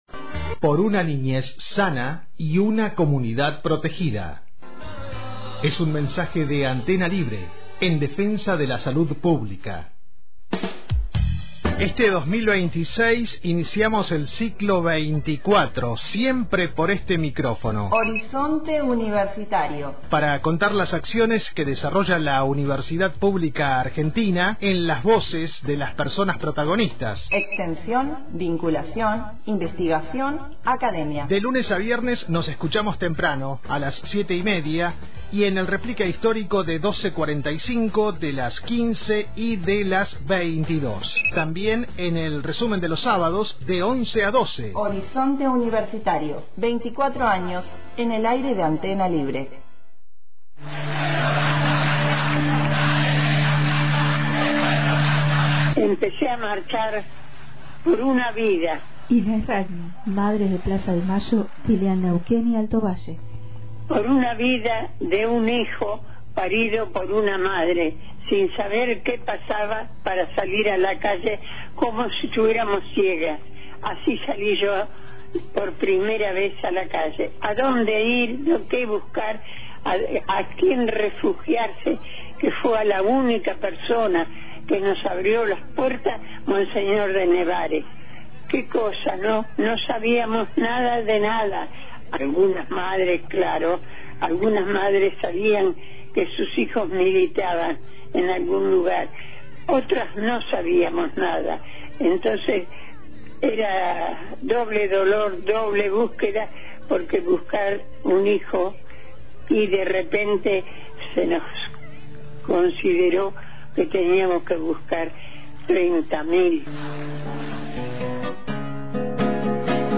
Durante la entrevista evocó también su compromiso con las causas populares y advirtió sobre el presente político, al que definió como un tiempo “cruel”.